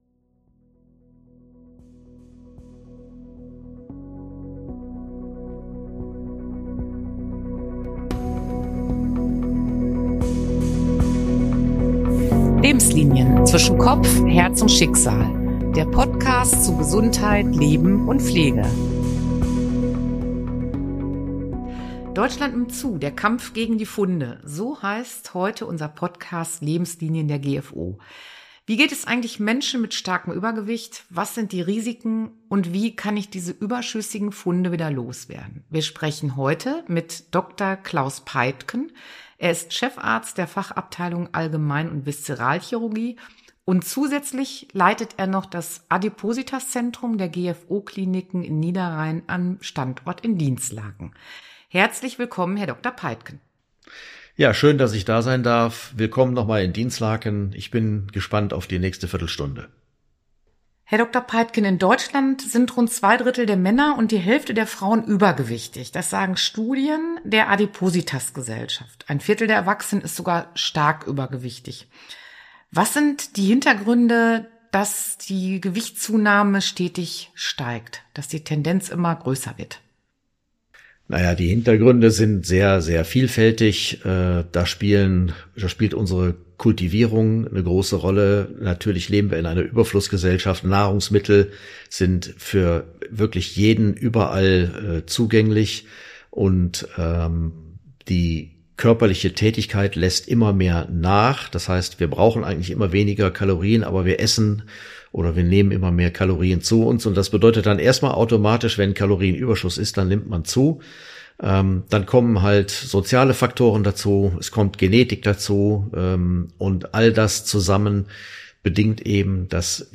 Wir sprechen mit dem Experten über die Unterschiede des Übergewichtes sowie Risken und Behandlungsmethoden. Dabei geht es unter anderem um den Einsatz von Abnehmspritzen, um Diäten oder chirurgischen Eingriffen. Schnell wird klar: Übergewicht ist nicht nur ein körperliches Problem, sondern ein soziales.